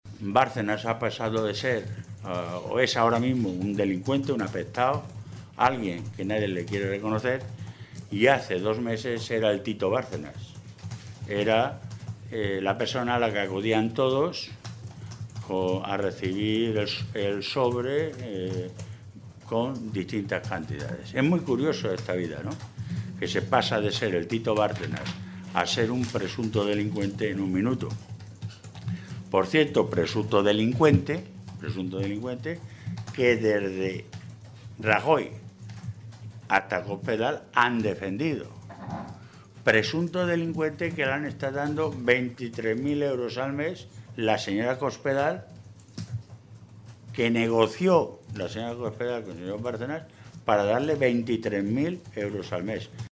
Vaquero hacía este anuncio esta mañana, en Toledo, en una comparecencia ante los medios de comunicación en la que advertía que sería “un error”, que la mayoría del PP en la Mesa de la Cámara se negara a calificar y tramitar la propuesta socialista.
Cortes de audio de la rueda de prensa